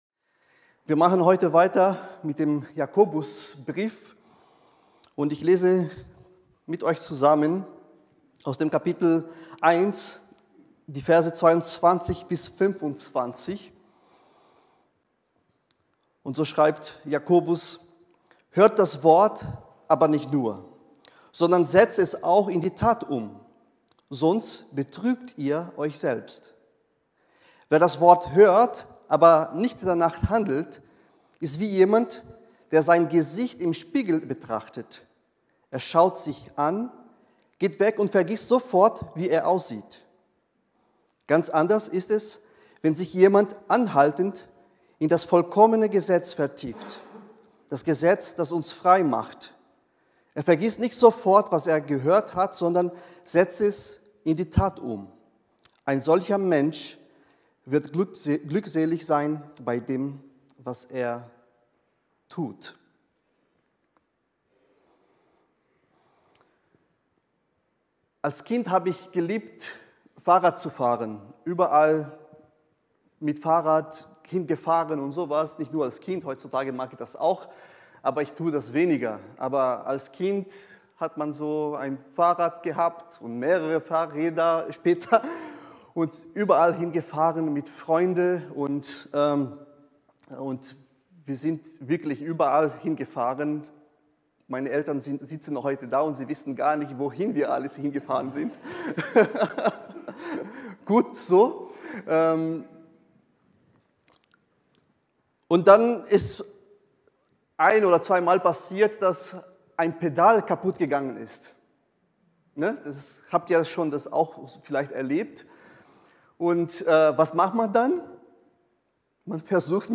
Predigten über den christlichen Glauben und Leben